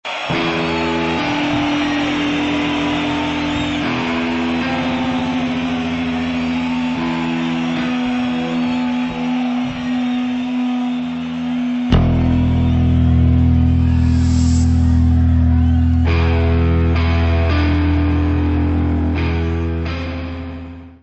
baixo
bateria
guitarra, voz
coro, percussão.
Área:  Pop / Rock